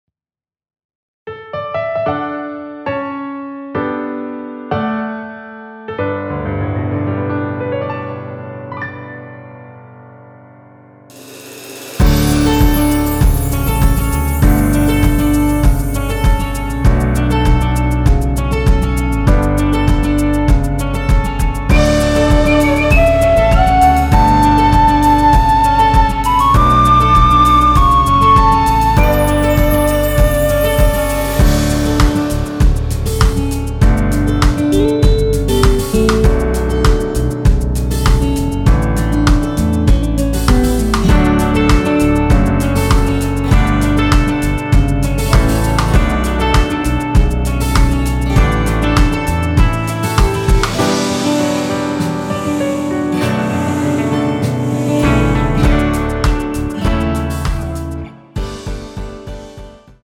원키에서(-6)내린(1절삭제) MR입니다.
앞부분30초, 뒷부분30초씩 편집해서 올려 드리고 있습니다.